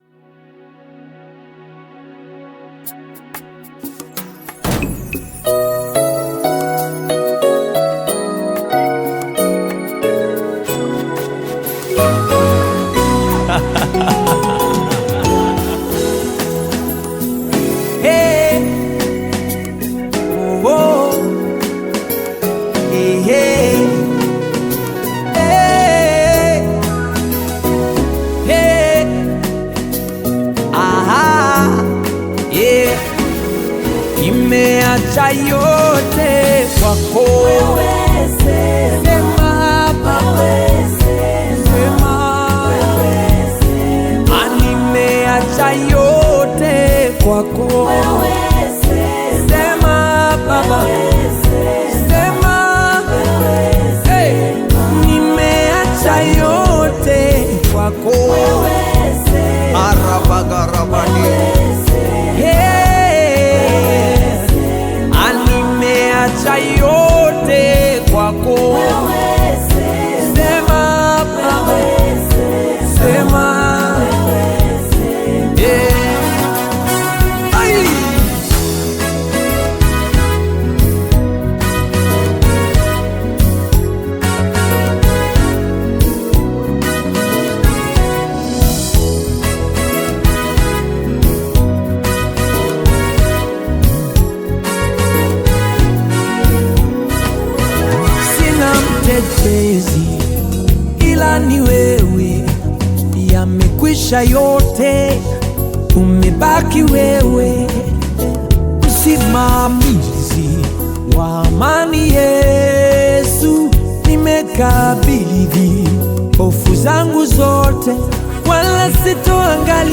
Gospel music track
Tanzanian gospel artist, singer, and songwriter